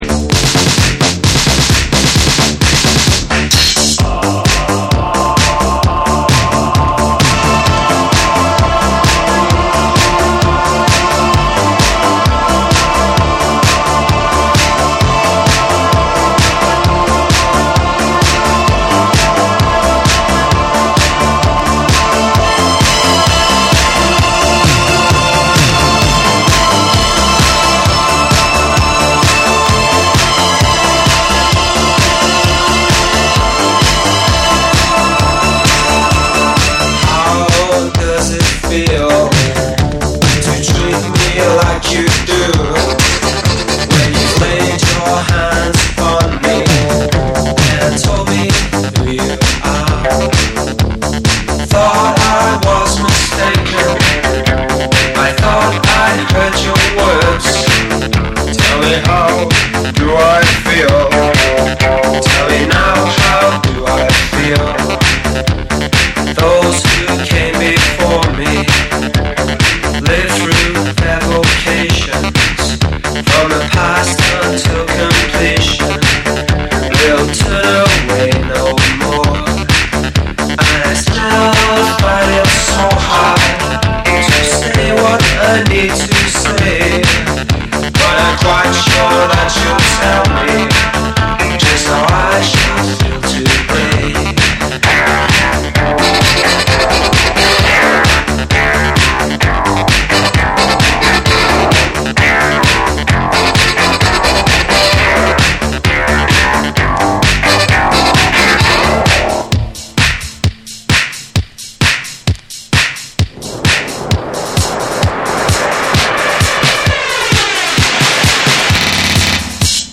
NEW WAVE & ROCK